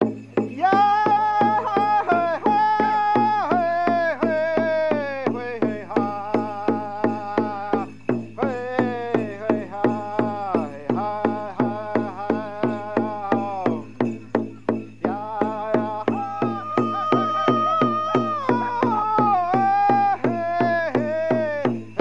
Звуки индейского племени при изгнании злых духов